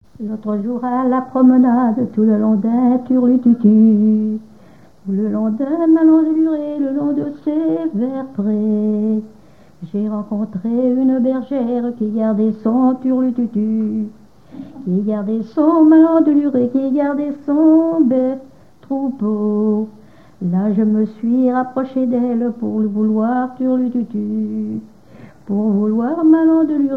danse : ronde : demi-rond
chansons à danser ronds et demi-ronds
Pièce musicale inédite